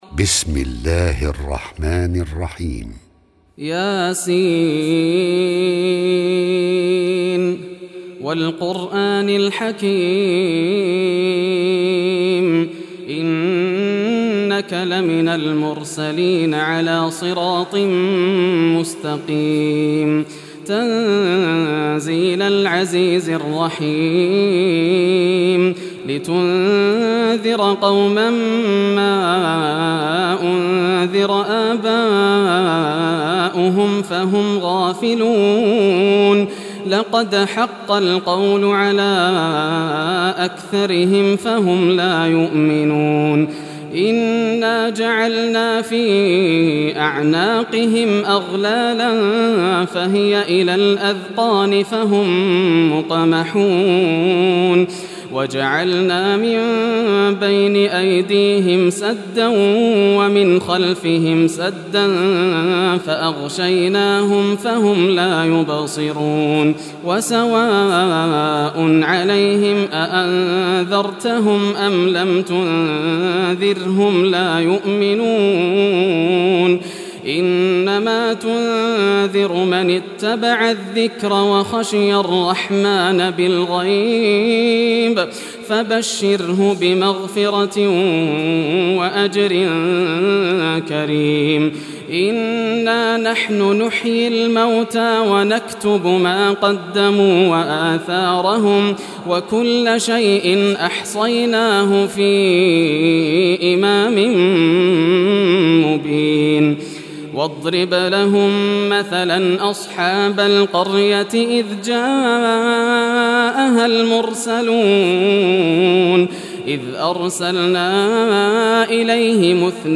Surah Yasin Recitation by Yasser al Dosari
Surah Yasin, listen or play online mp3 tilawat / recitatation in Arabic in the beautiful voice of Sheikh Yasser al Dosari.